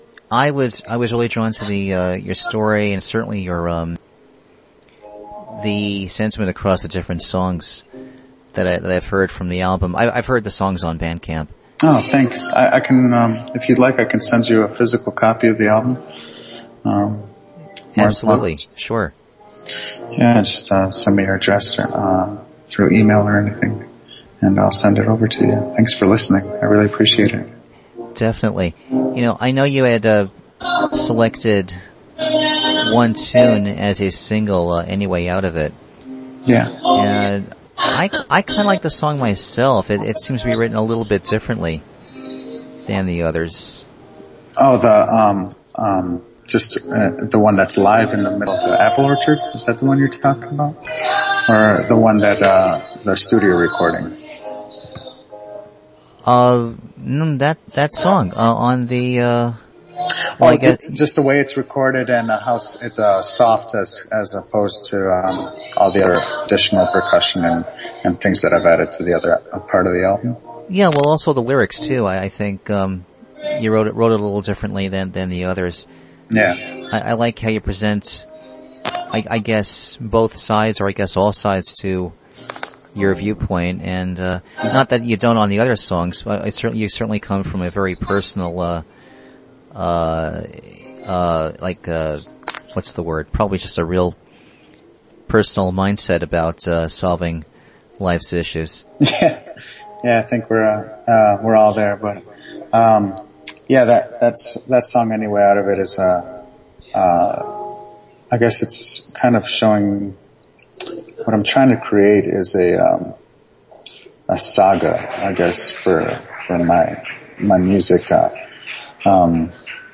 Interview took place via Skype as part of Cyber PR’s Digital Press Conference, 7/31/13.